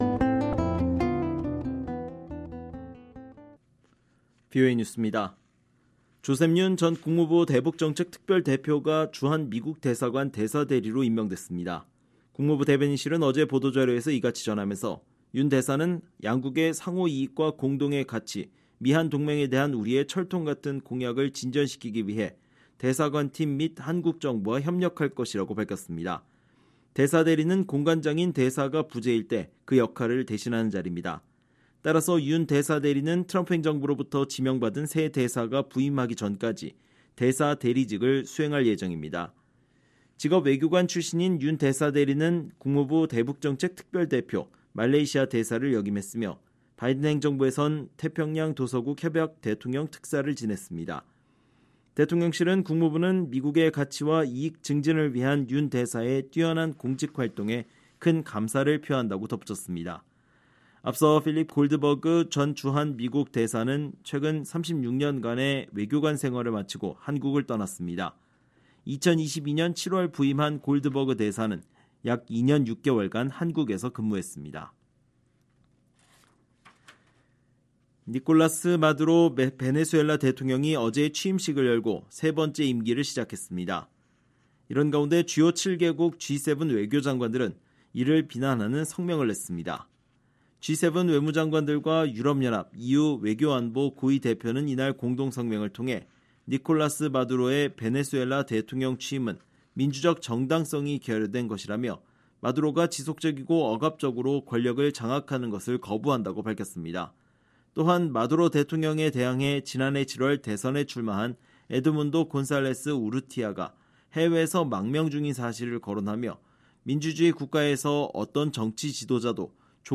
VOA 한국어 방송의 토요일 오후 프로그램 2부입니다.